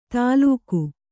♪ tālūku